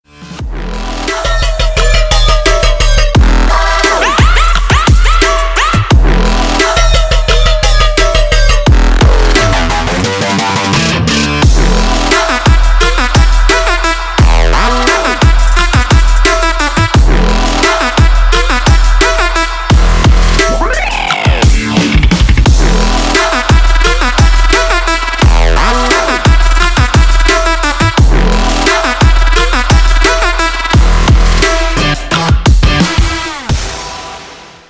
Дабстеп рингтоны